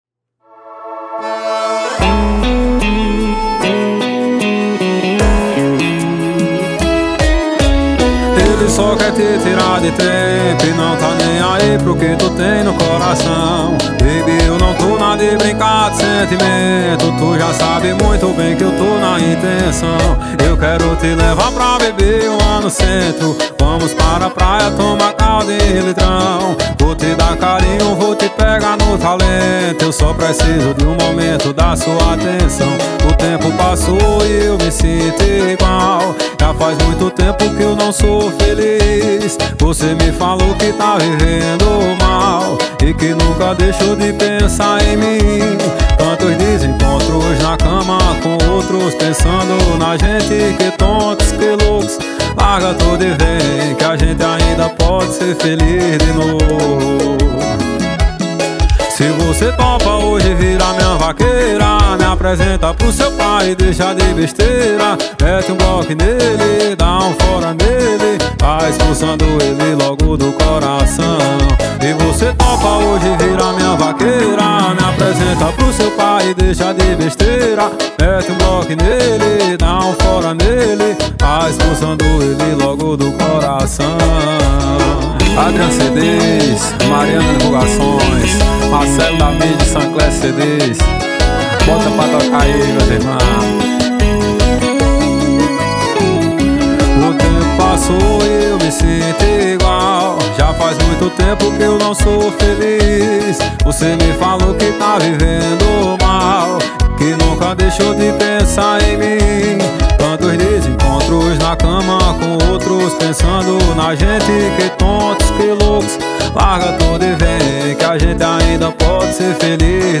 2024-02-14 17:56:55 Gênero: Forró Views